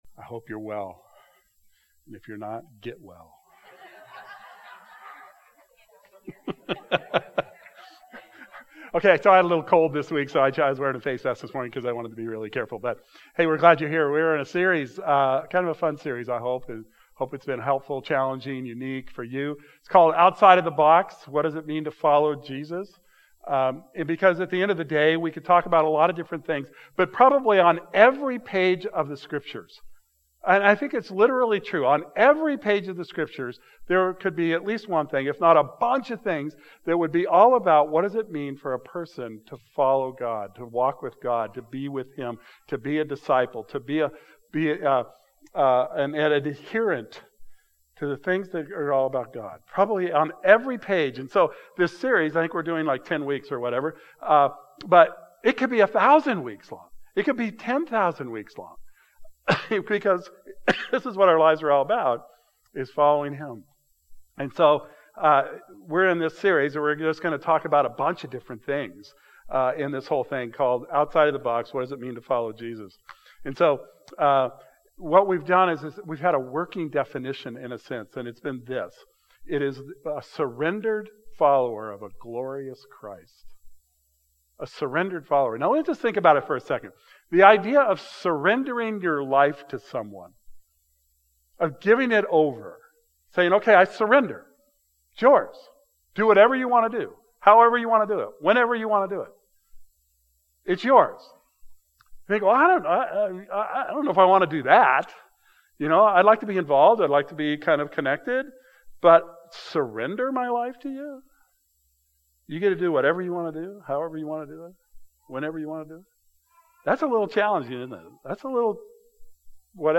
This morning we look at Evangelism and the fact that it's not a project or a program, but a state of being. The story of Andrew and Peter demonstrates this idea. Communion will be offered, so feel free to get the elements for that so you can participate from where you are.